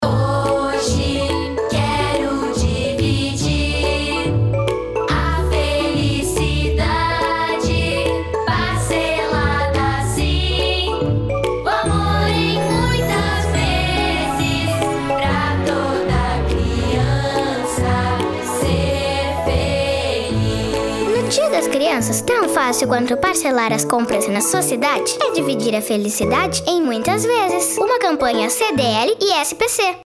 jingle alusivo à data
JINGLE 30'' Download